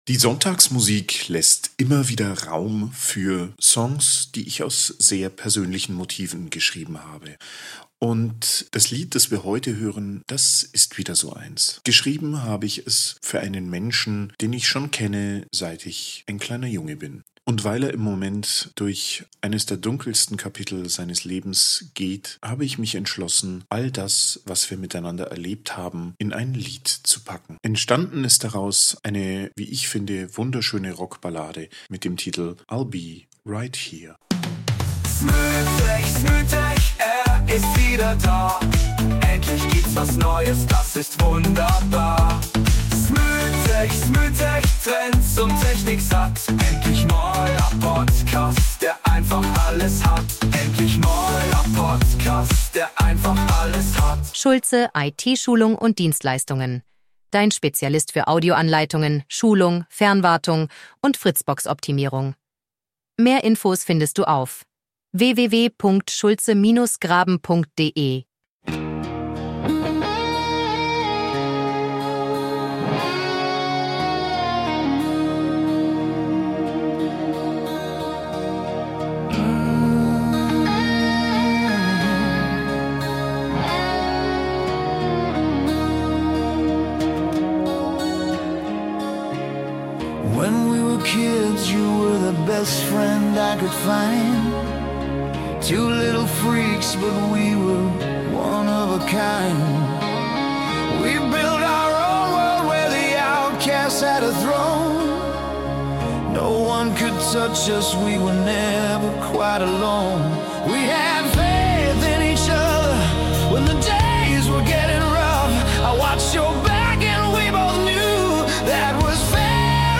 Rockballade.